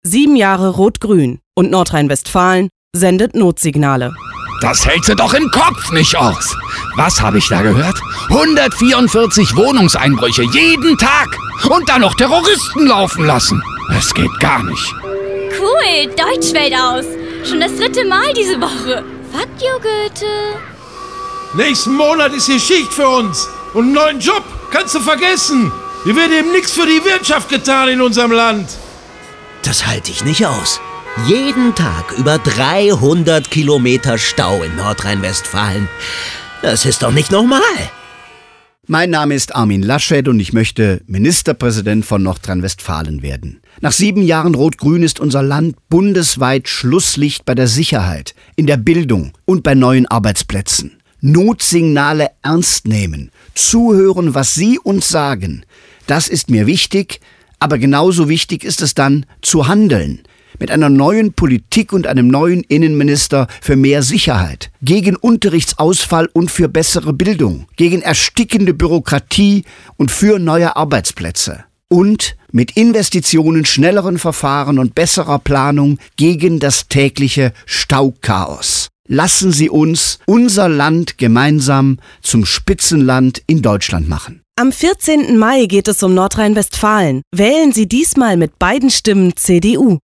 Audio-DateiFunkspot der CDU Nordrhein-Westfalen zur Landtagswahl 2017 (3 MB)